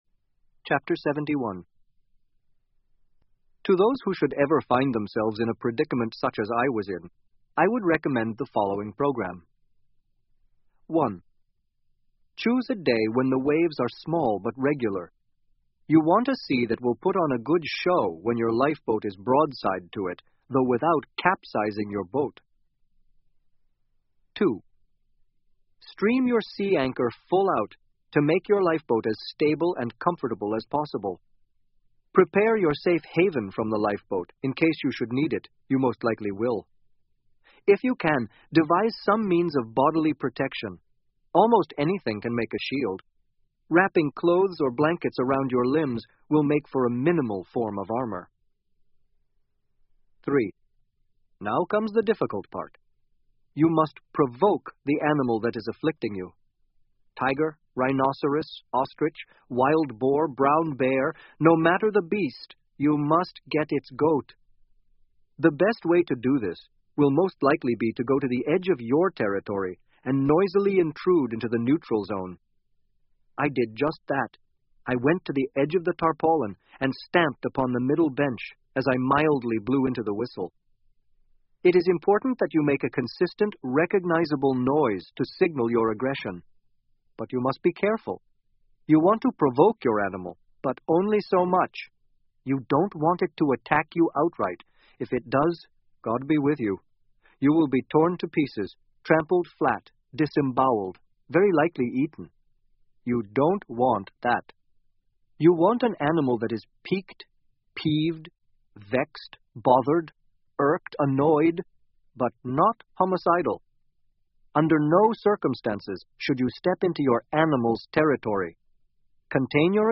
英文广播剧在线听 Life Of Pi 少年Pi的奇幻漂流 06-12 听力文件下载—在线英语听力室